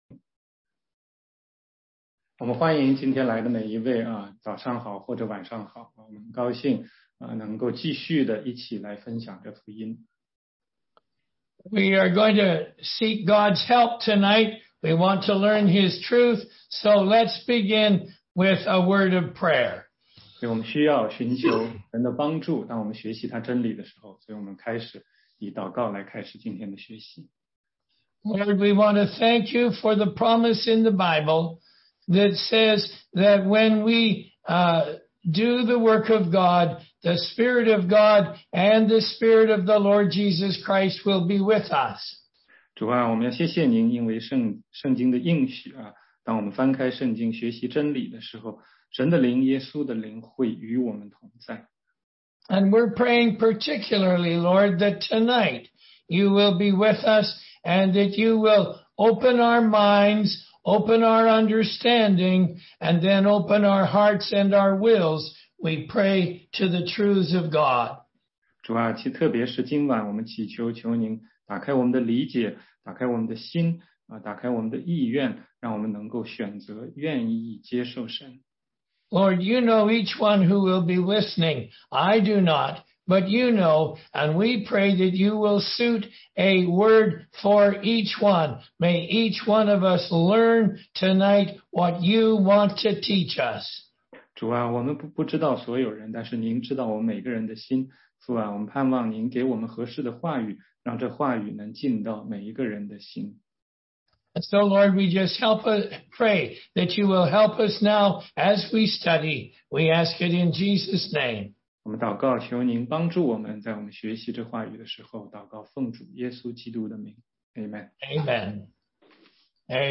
16街讲道录音 - 福音课第七讲